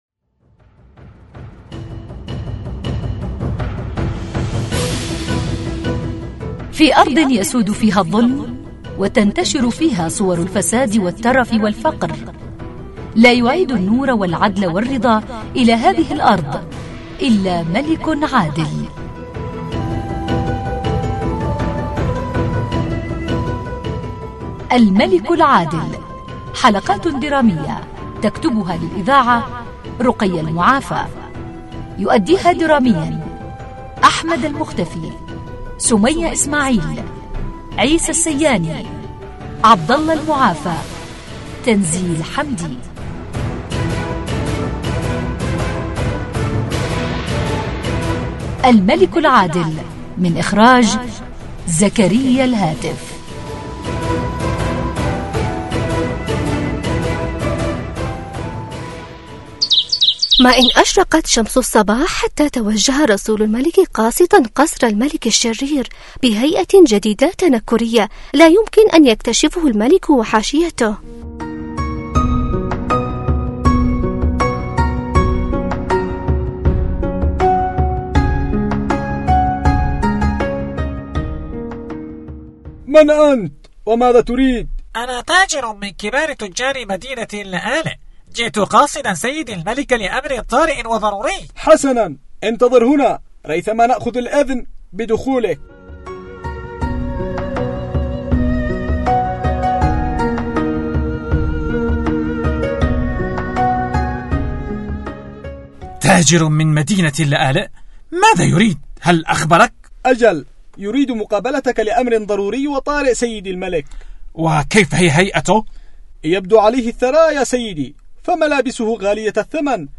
برامج درامي للأطفال يحكي عن الملك العادل